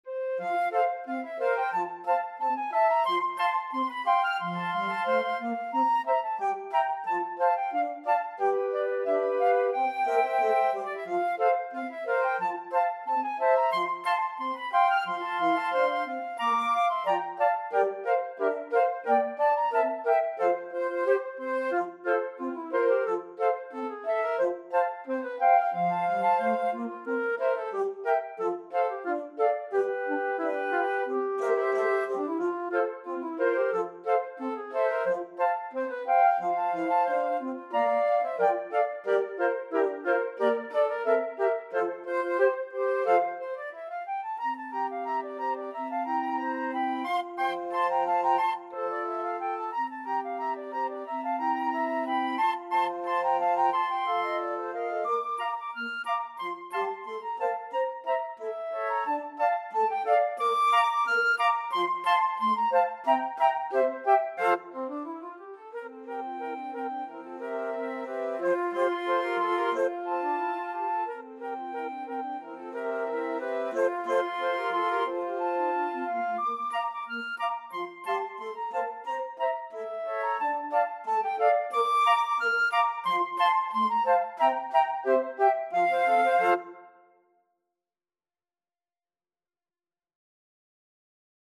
Flute 1Flute 2Alto FluteBass Flute
4/4 (View more 4/4 Music)
Flute Quartet  (View more Intermediate Flute Quartet Music)
Traditional (View more Traditional Flute Quartet Music)